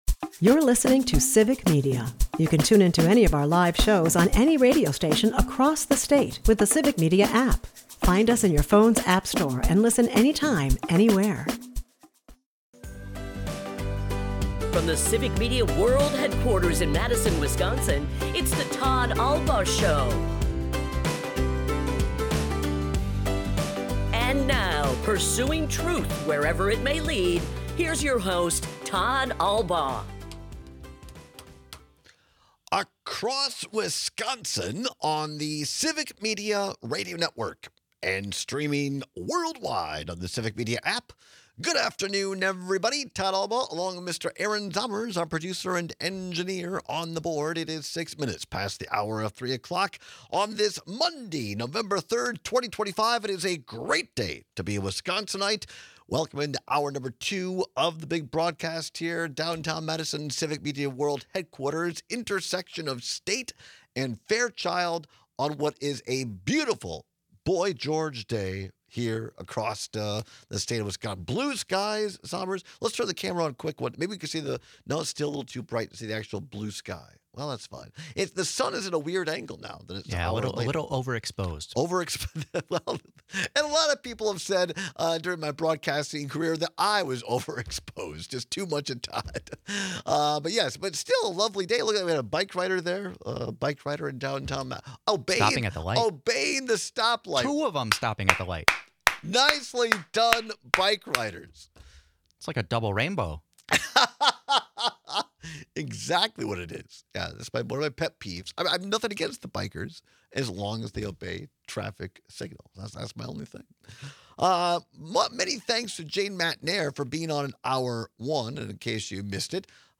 We take some texts and calls on whether it stings worse to be sad or angry when things go awry.